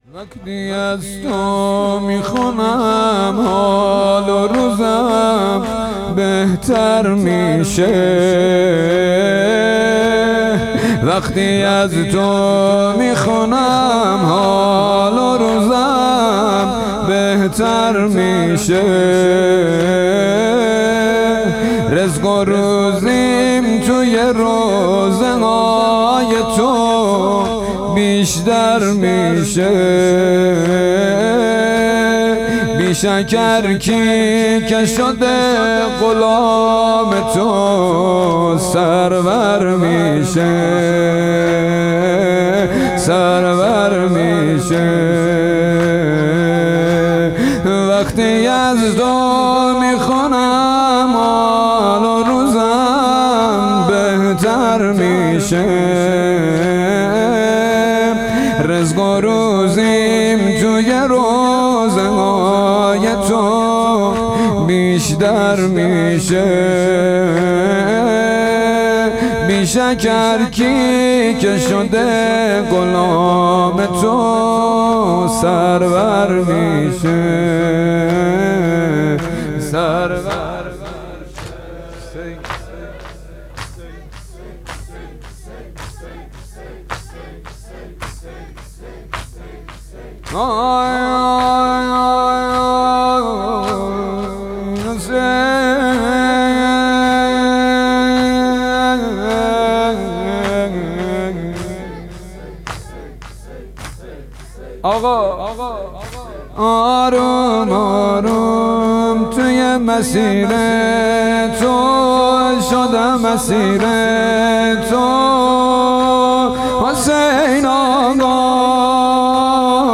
حسینیه بیت النبی - فایل صوتی جلسه هفتگی 19-11-99